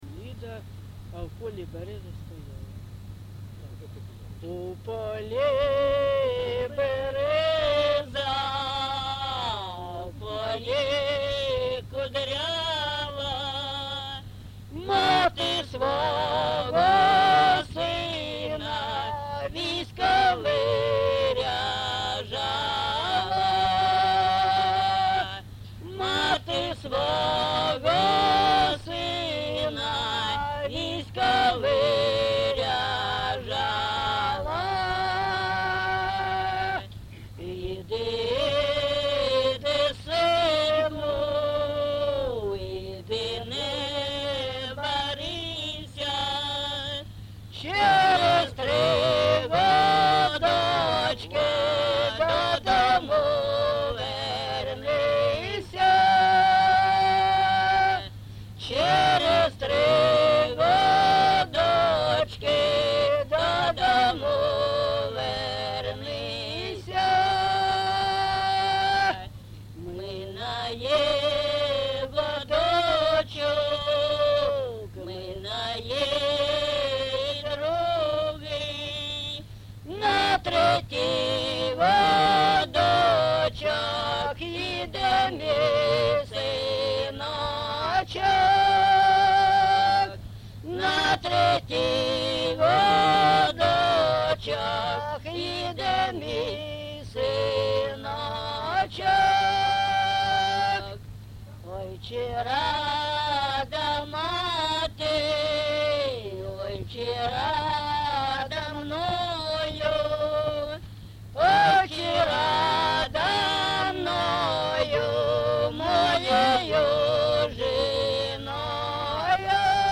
ЖанрПісні з особистого та родинного життя, Балади
Місце записус-ще Щербинівка, Бахмутський район, Донецька обл., Україна, Слобожанщина